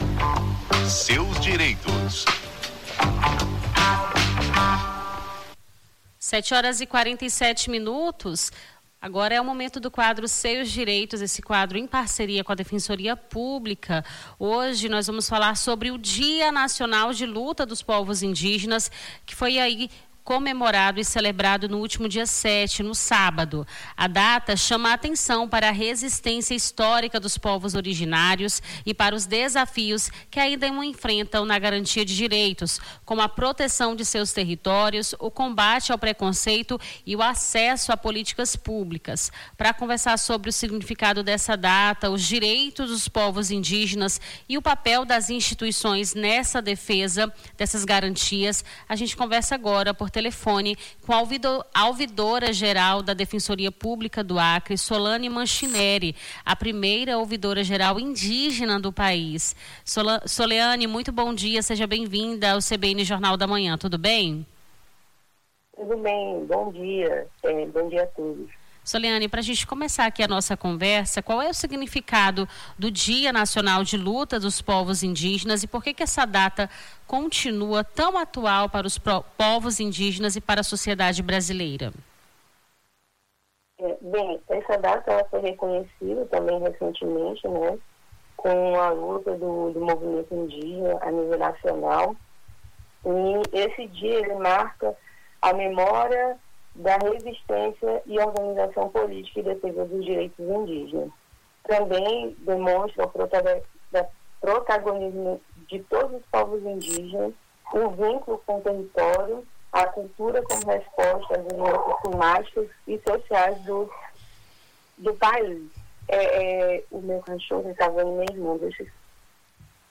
Na manhã desta segunda-feira, 09, conversamos com a ouvidora-geral da defensoria pública do acre, Soleane Manchineri, sobre o direito dos povos indígenas.